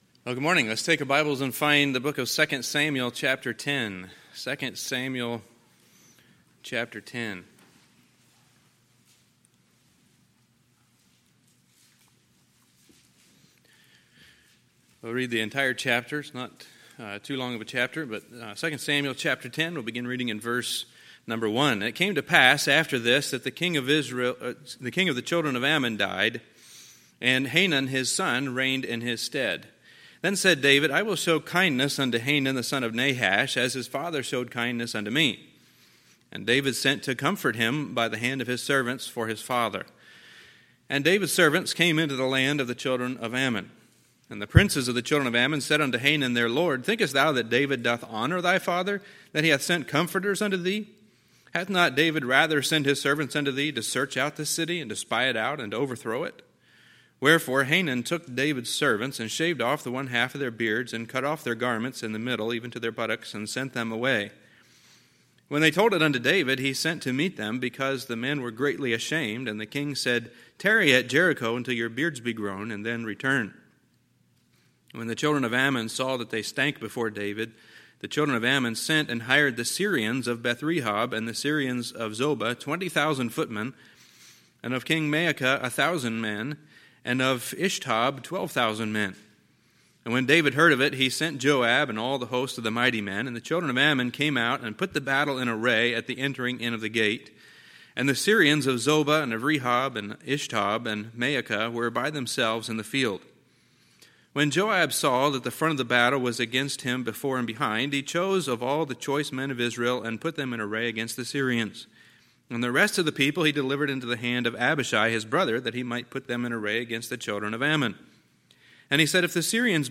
Sunday, August 9, 2020 – Sunday Morning Service
Sermons